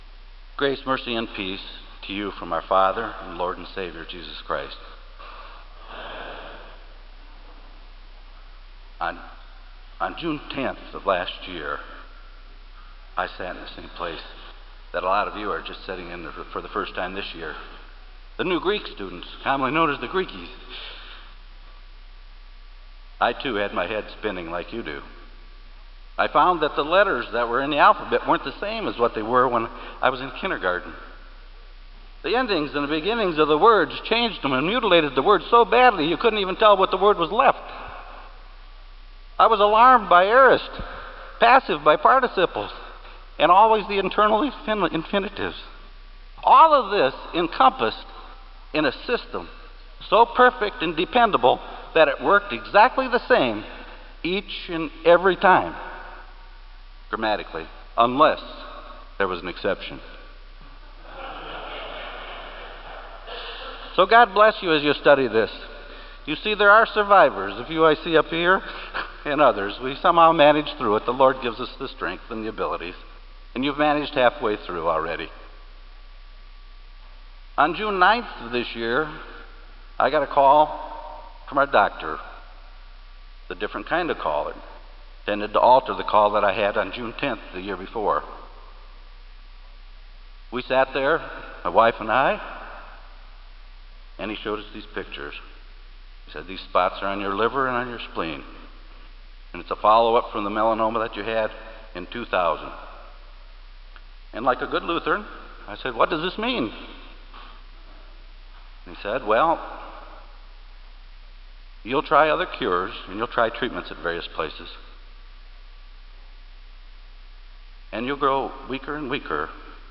Kramer Chapel Sermon - July 08, 2003